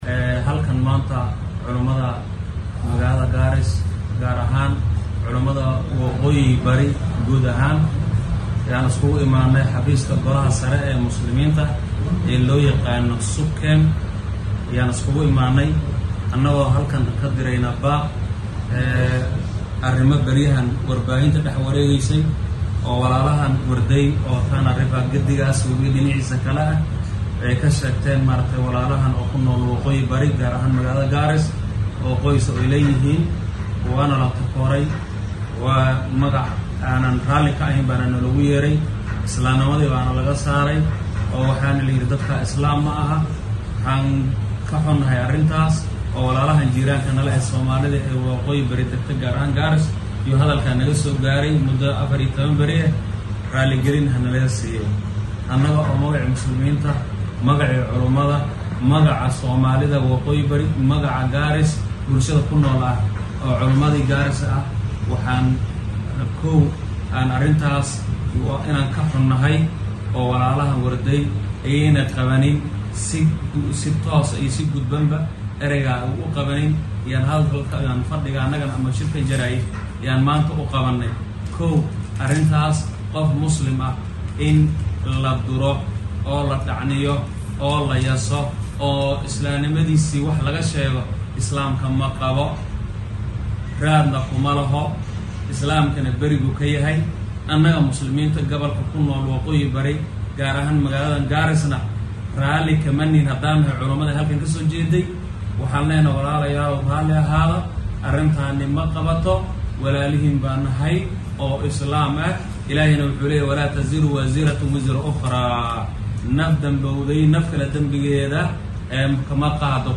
Culimada ismaamulka Garissa oo shir jaraaid ku qabtay xafiiska golaha sare ee muslimiinta dalka ee SUPKEM ee magaalada Garissa ayaa raalligelin ka bixiyay hadal dhawaan la jeediyay kaasi oo ay dhibsatay bulshada ku nool ismaamulka Tana River.